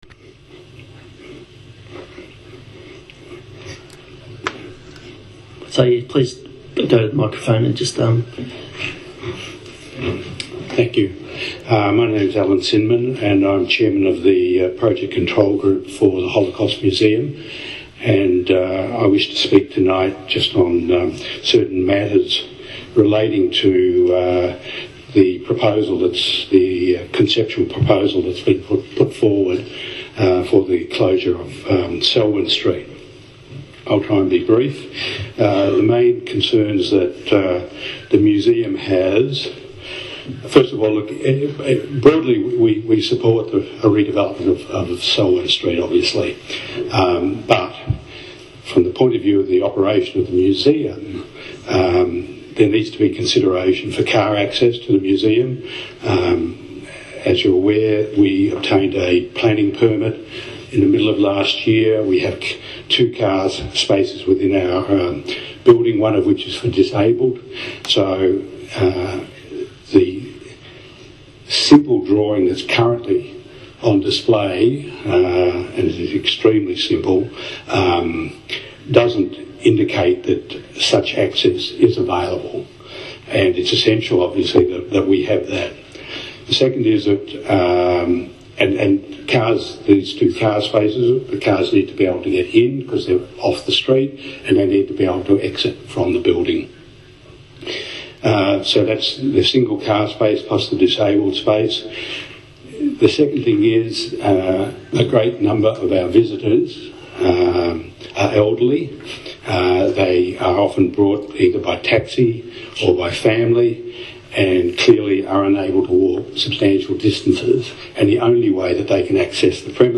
Tonight’s speakers on the Selwyn Street/Gordon Street closures had one thing in common. Each and every speaker was strongly opposed to council’s plans.